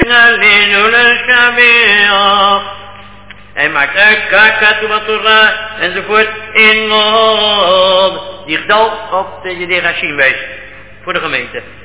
Sung